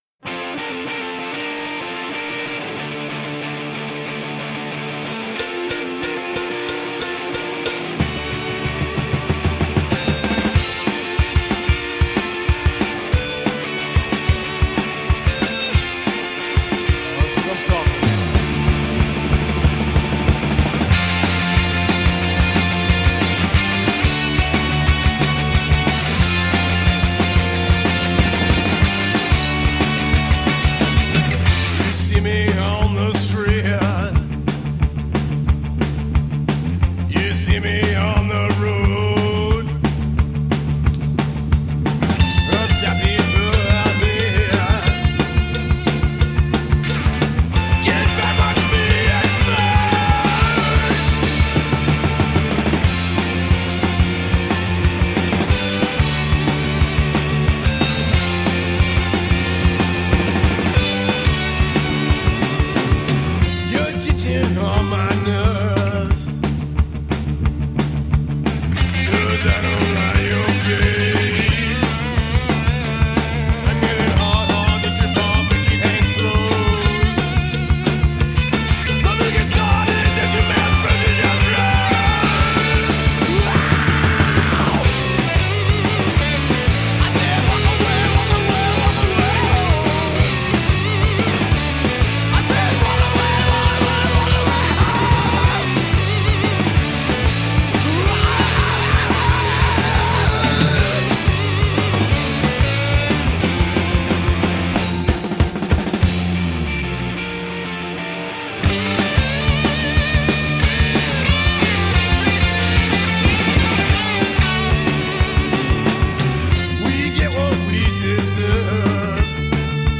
Punk-n-roll.
Powerful and melodic, fast rock-n-roll from New York City!
Tagged as: Hard Rock, Punk, Rock, High Energy Rock and Roll